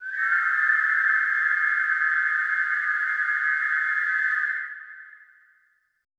WHIS CLS  -L.wav